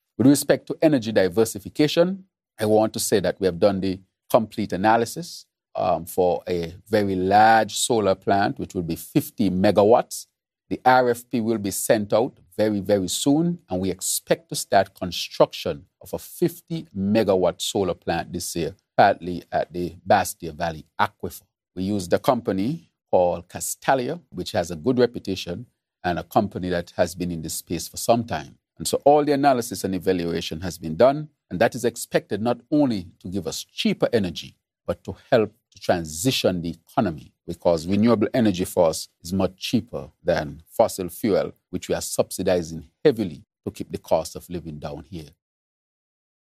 Earlier this month on January 8th, Prime Minister, the Hon. Dr. Terrance Drew shared this plan for energy diversity over the coming months: